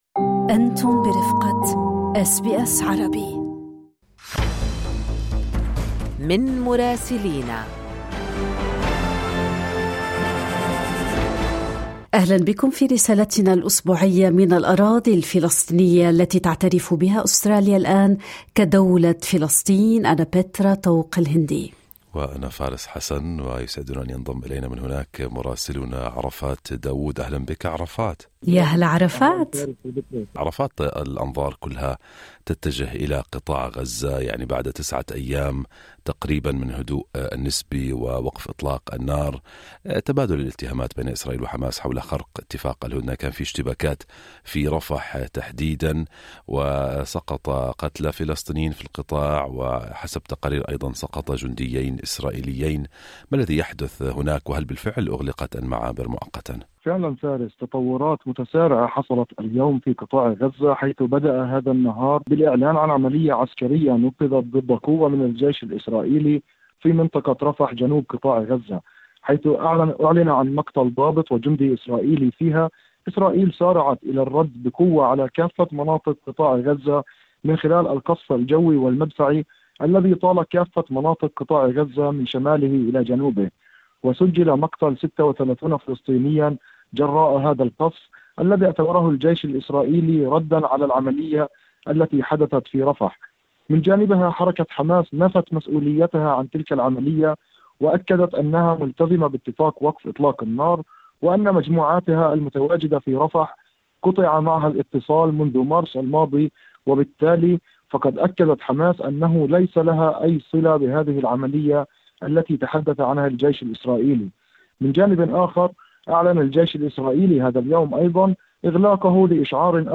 تجددت الغارات الإسرائيلية على قطاع غزة وأوقعت قتلى وجرحى في الوقت الذي تبادلت فيه إسرائيل وحماس تهم خرق اتفاق الهدنة. اقتحامات متزامنة في الضفة الغربية المحتلة ترافقت مع هجمات مستوطنين على مزارعي الزيتون. التفاصيل مع مراسلنا في رام الله